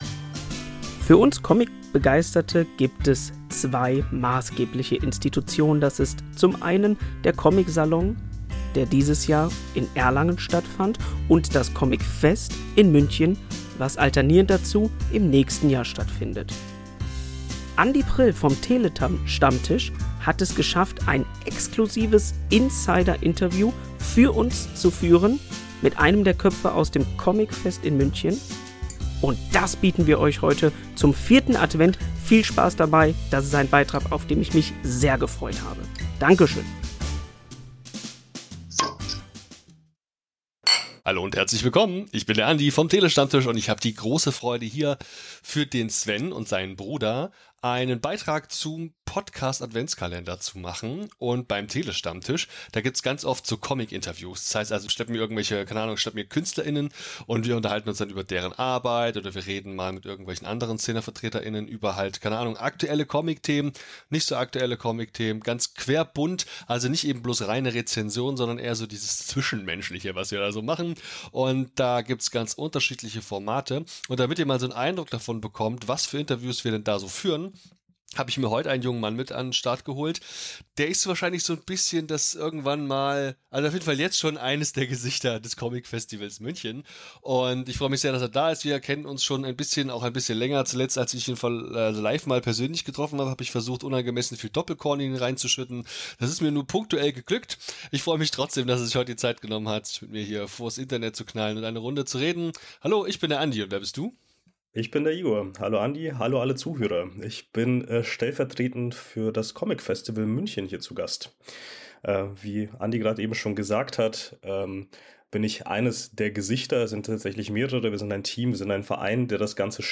Insiderlook